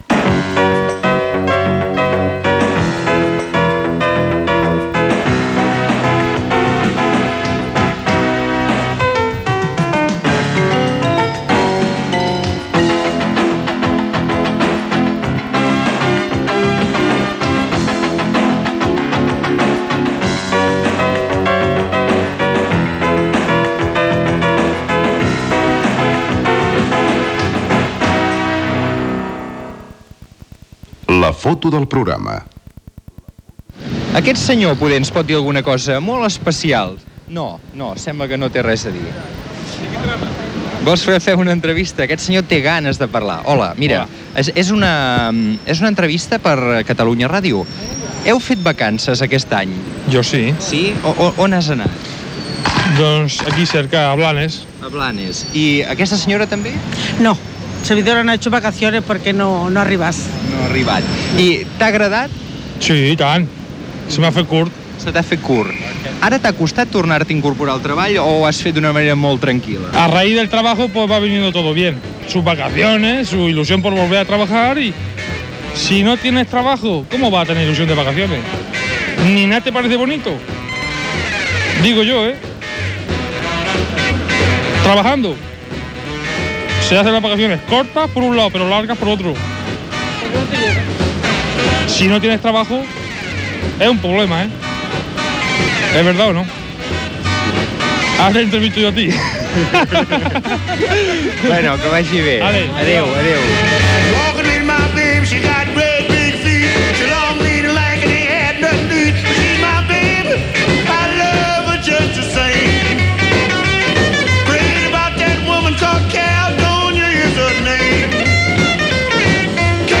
Sintonia de l'emissora i inici del programa amb respostes sobre les vacances, careta del programa, dutxa freda, sumari del programa i propostes culturals.
Entreteniment
FM